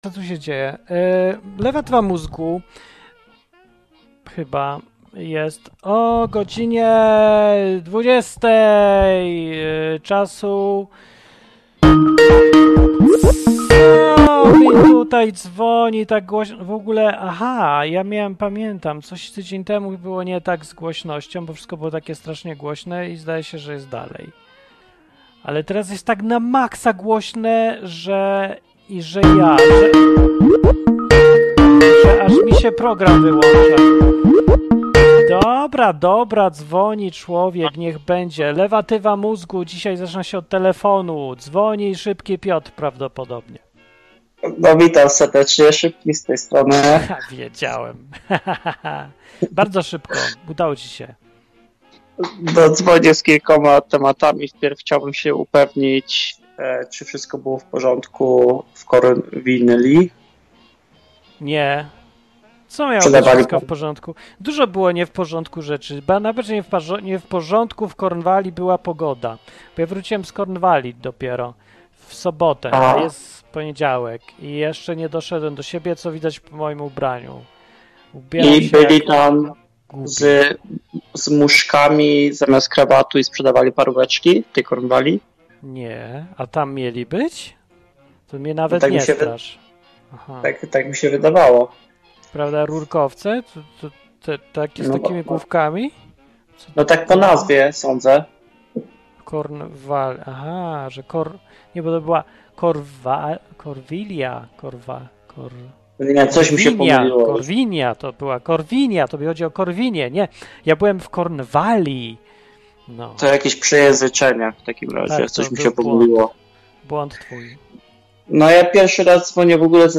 W tym odcinku o podróży po Kornwalii, telefony wyjaśniające, że ziemia jest piłą oraz teorie globalnego ocieplenia. Są wakacje, wiec słuchacze pozwalają sobie na więcej.
Program satyryczny, rozrywkowy i edukacyjny.